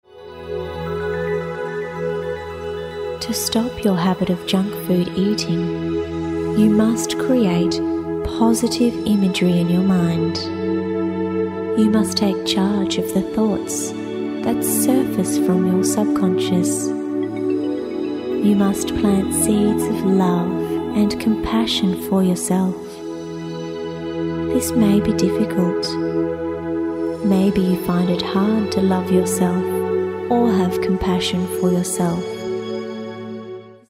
Self-Hypnosis for Junk Food Addictions